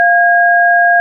DTMF keypad frequencies (with sound clips)[12]
1209 Hz 1336 Hz 1477 Hz 1633 Hz